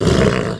minotaur_damage.wav